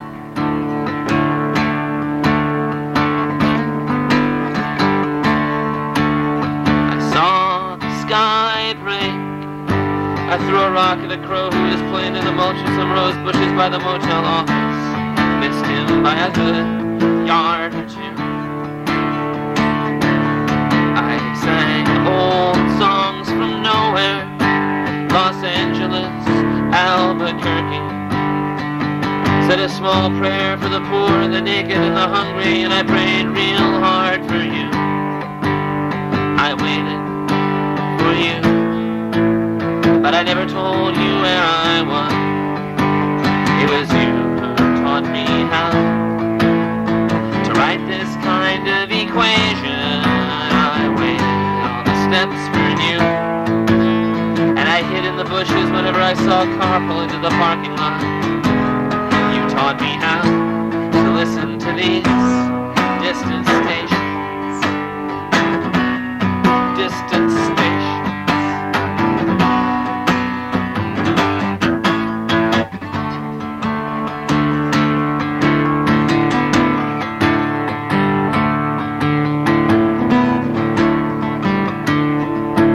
had a cool guitar hook
heavy on the wheel grind